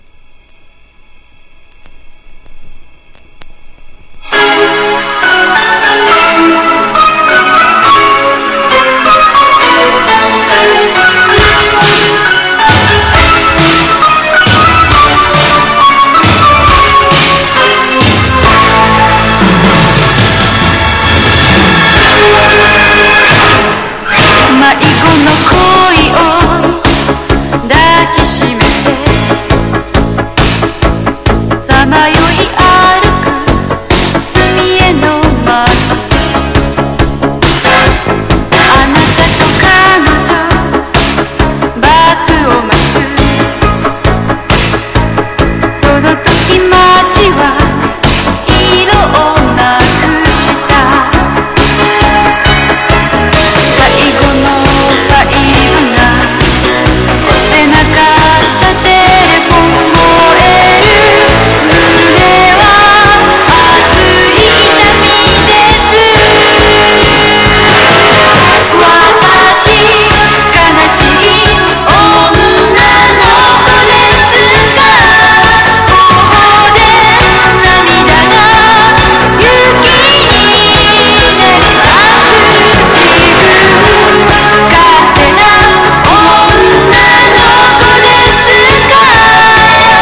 POP
ポピュラー# 70-80’S アイドル